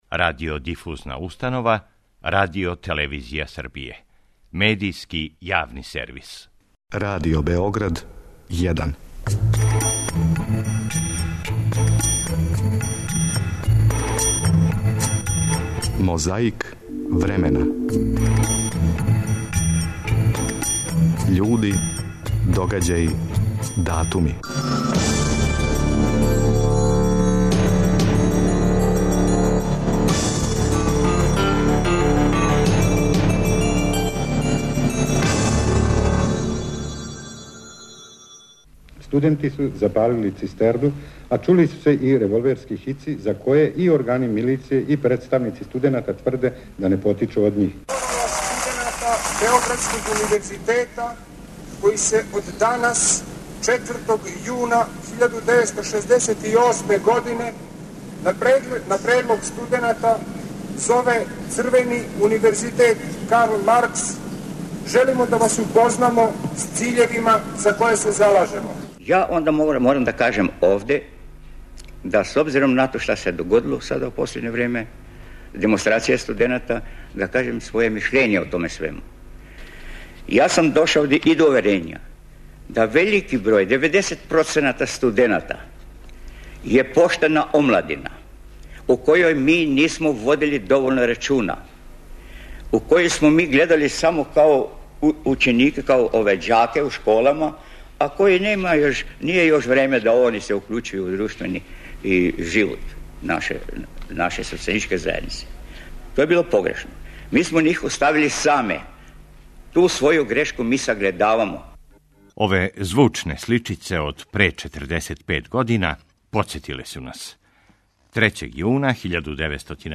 Звучне сличице од пре 45 година отварају ову борбу против пилећег памћења, а подсећају на 3. јун 1968. када су почеле студентске демонстрације у Београду.